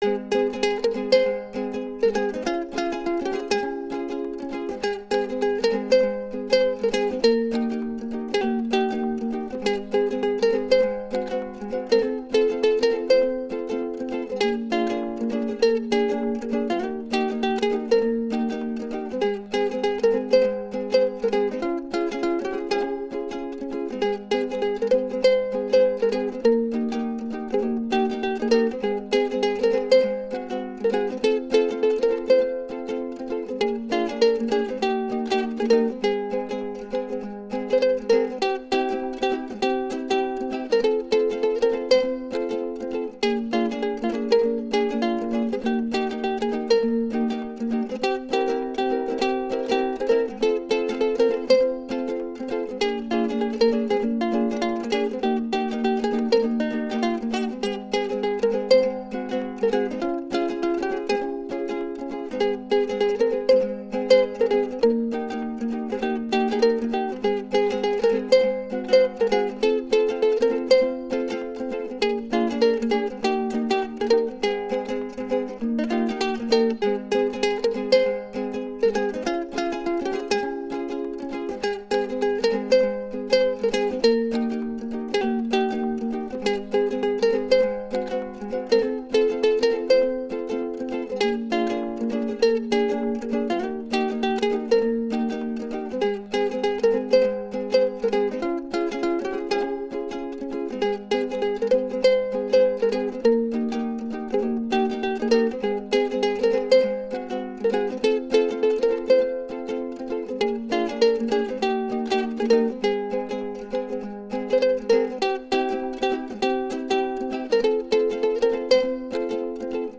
I feel like sum island musik, mon…